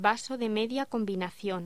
Locución: Vaso de media combinación
voz